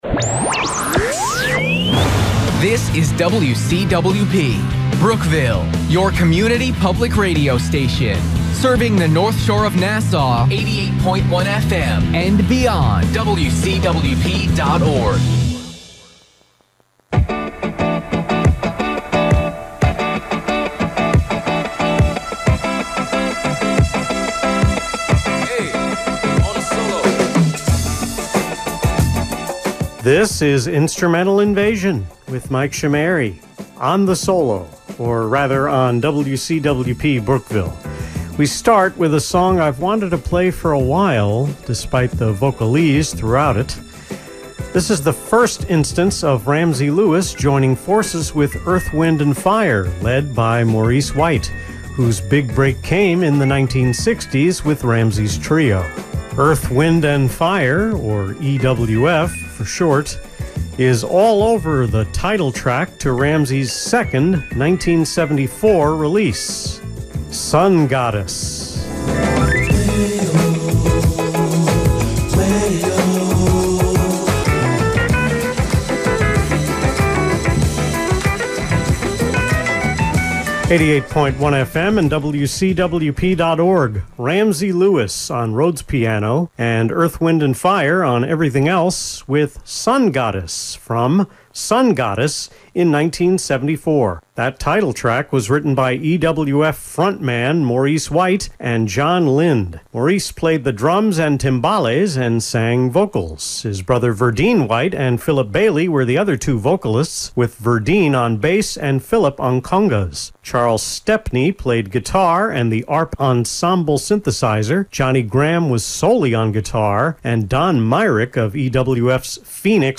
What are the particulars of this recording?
I didn’t have to cut much from the rest of the script, but still had to fade songs down early in several cases. (That refers to “hitting the post,” ending just as the main melody or vocals begin.)